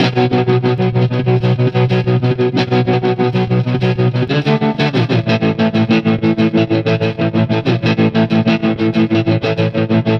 Trem Trance Guitar 01h.wav